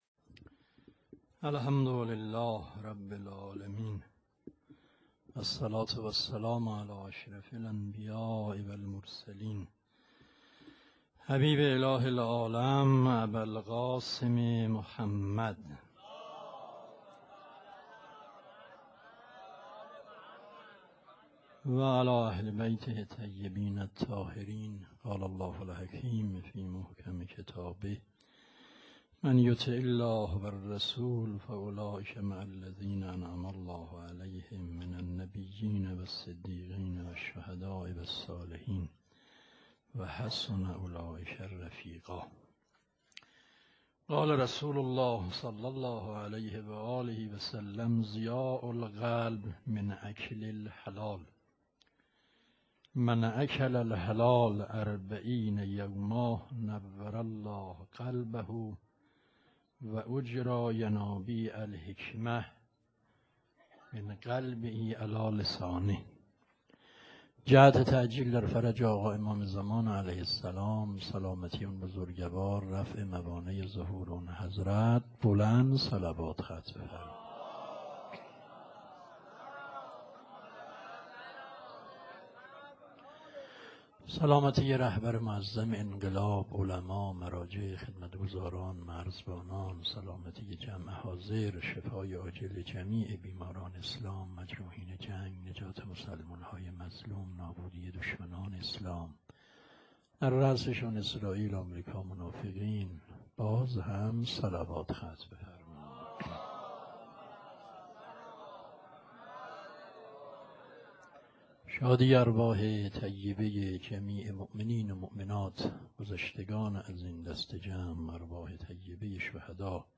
شب هفتم _ سخنرانی